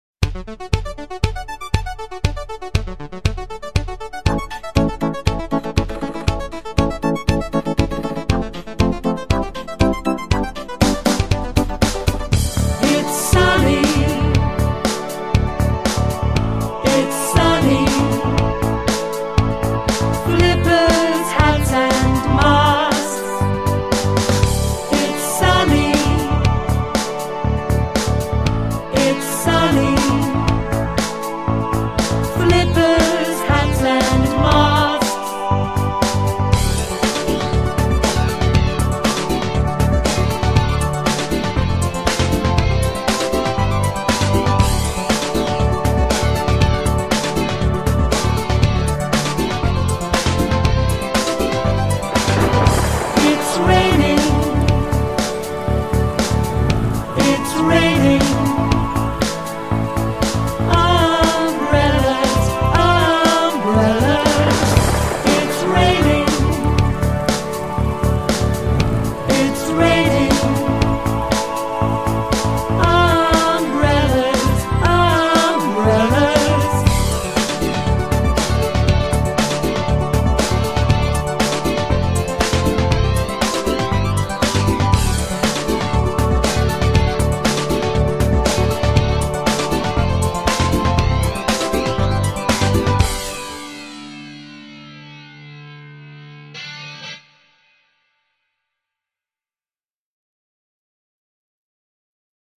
Обучающие песенки